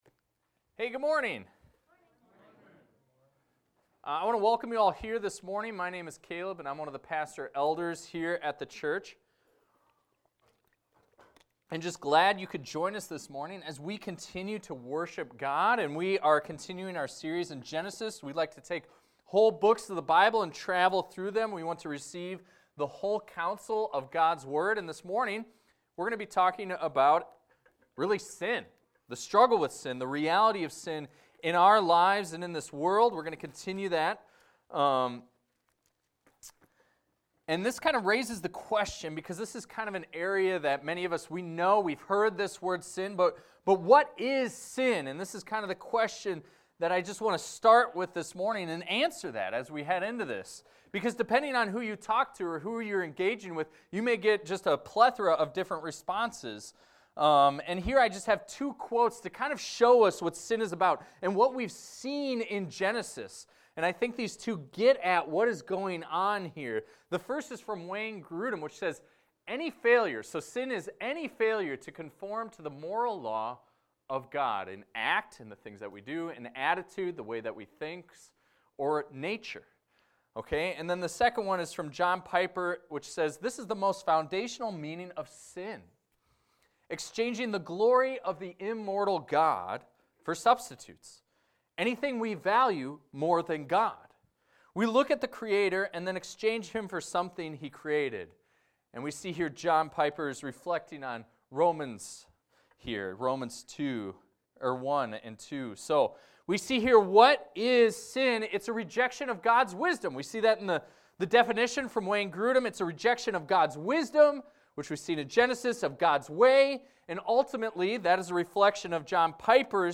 This is a recording of a sermon titled, "The Brother's Keeper."